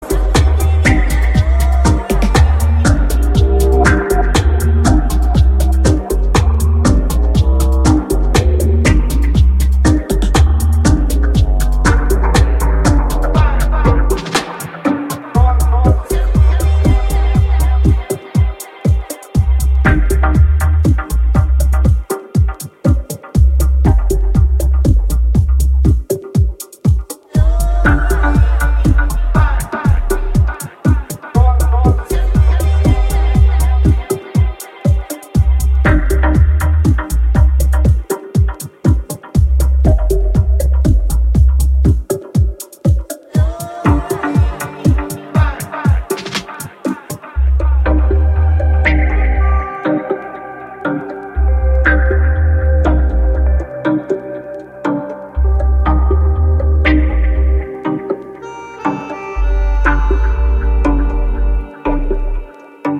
シロップのように甘くヘヴィなローエンド、郷愁に満ちたメロディカ、精霊のように漂うヴォーカル